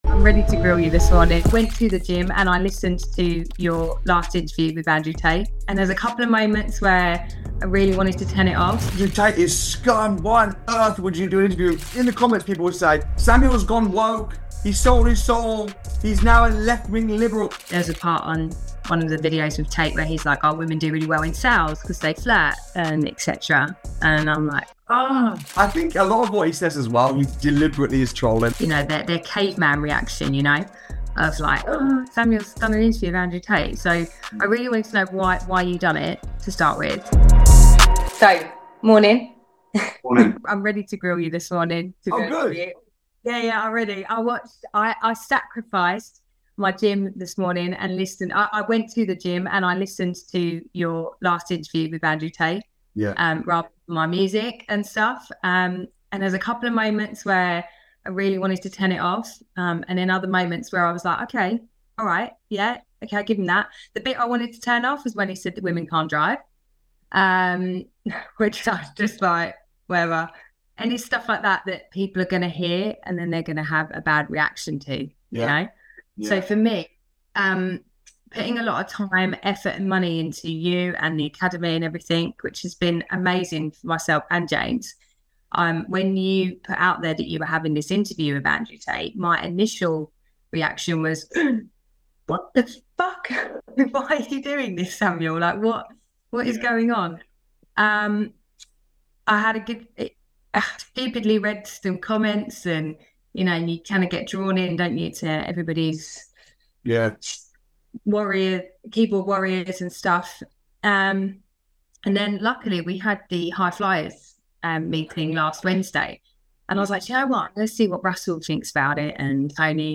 Being Grilled By Female Student on Tate Videos